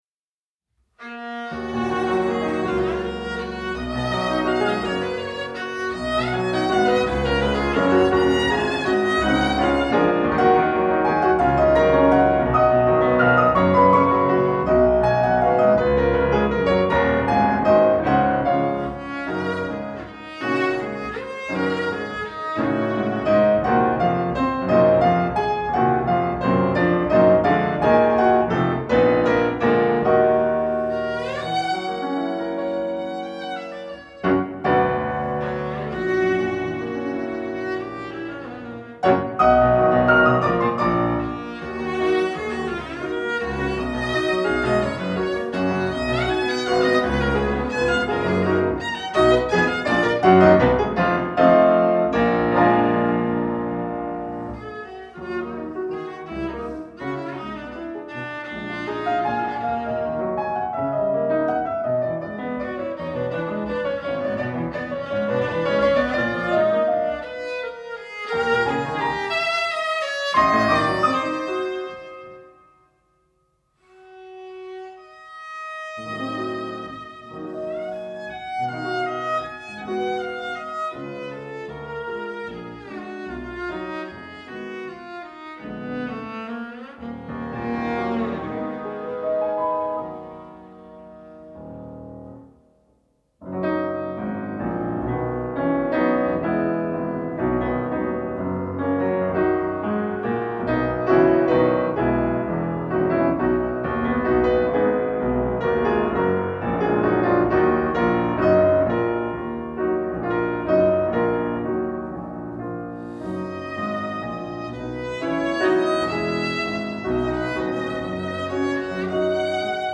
Sonata for Viola and Piano